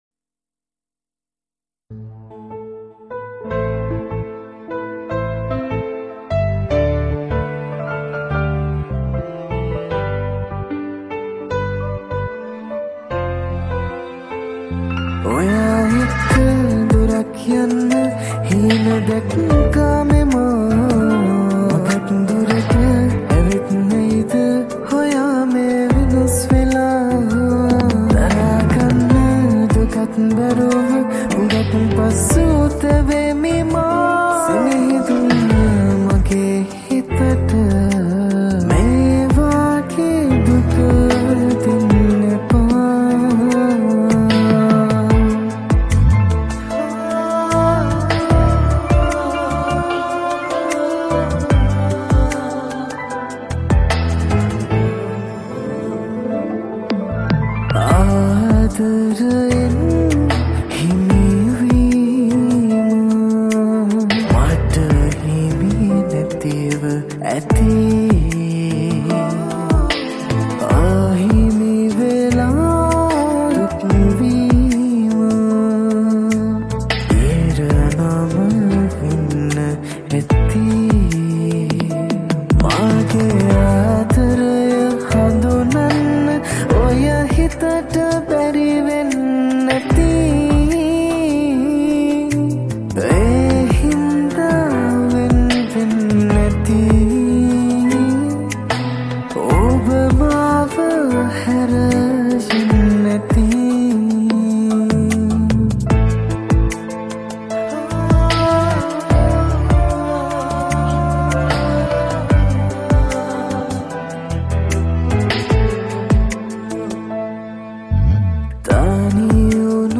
High quality Sri Lankan remix MP3 (3.1).